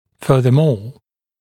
[ˌfɜːðə’mɔː][ˌфё:зэ’мо:]к тому же, более того